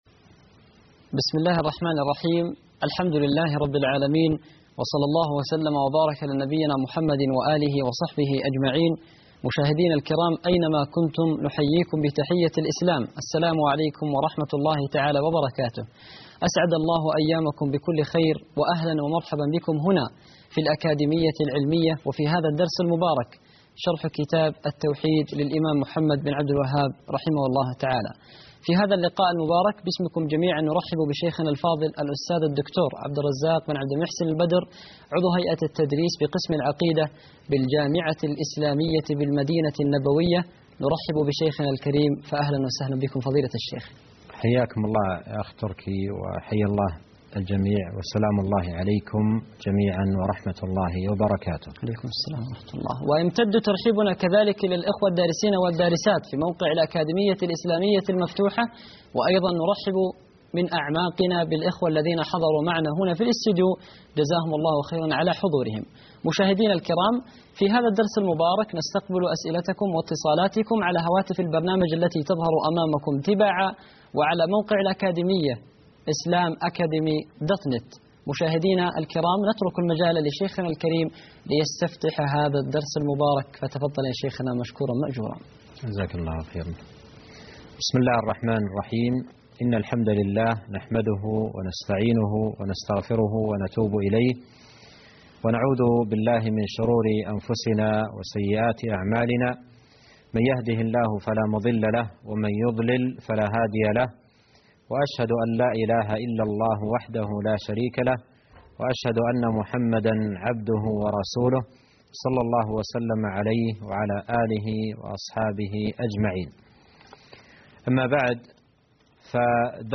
الدرس الثاني عشر _ الذبح (21/6/2011) شرح كتاب التوحيد